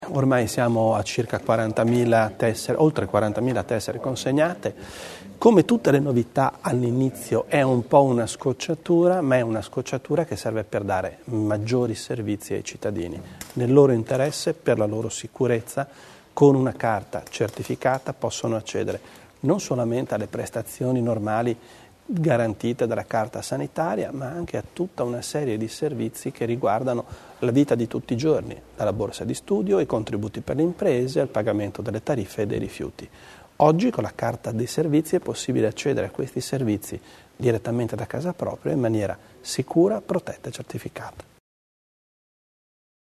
L'Assessore Bizzo sulle novità per la Carta Provinciale dei Servizi